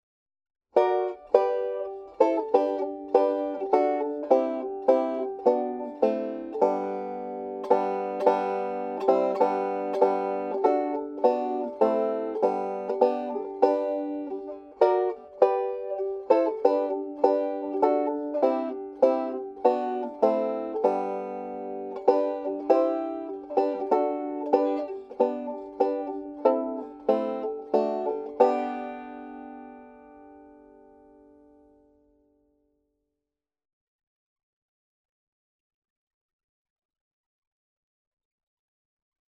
5-STRING BANJO SOLO Christmas, 5-String Solo, G Tuning
DIGITAL SHEET MUSIC - 5-STRING BANJO SOLO